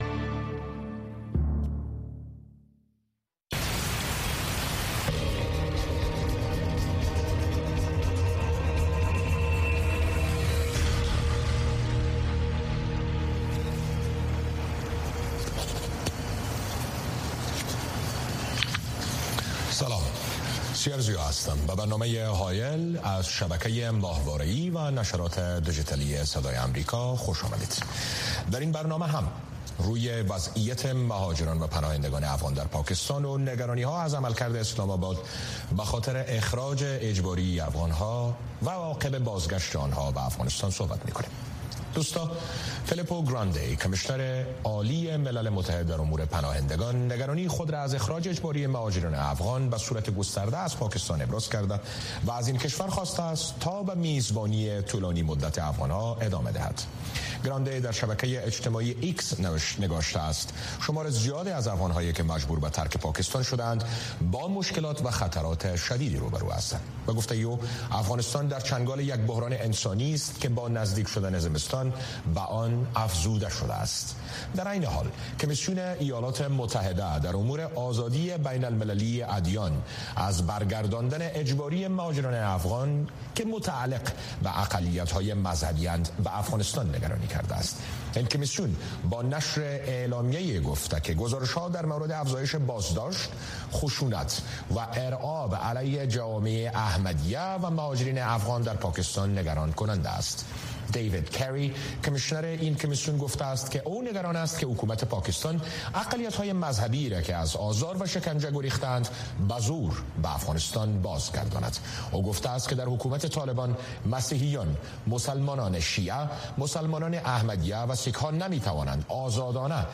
په دغه خپرونه کې د بېلابېلو اړخونو سره په مخامخ، ژورو او تودو بحثونو کې د افغانستان، سیمې، او نړۍ مهم سیاسي، امنیتي، اقتصادي، او ټولنیز موضوعات څېړل کېږي.